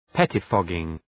Προφορά
{‘petı,fɒgıŋ}
pettifogging.mp3